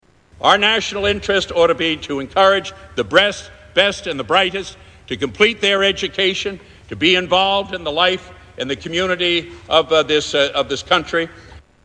Ted Kennedy - Breast From Freudian Slips - Live on the News !
Tags: Media Freudian Slips News Newscasters Funny